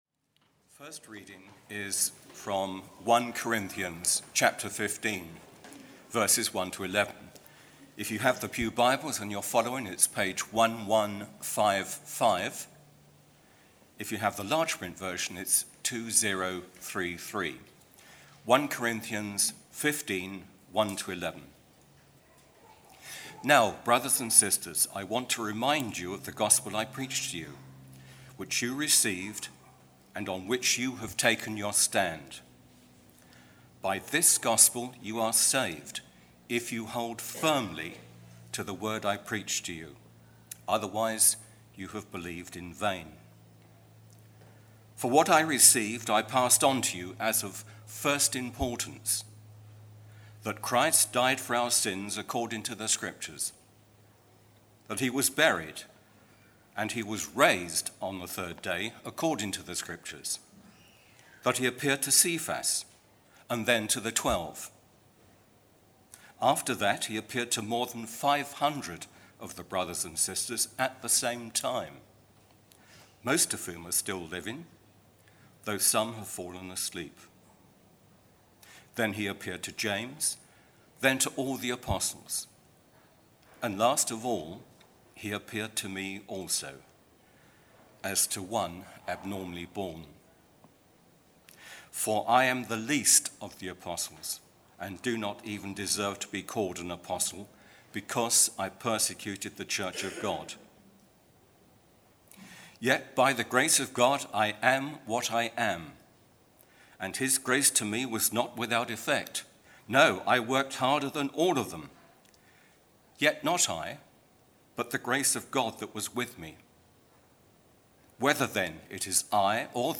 Theme: He is Risen Sermon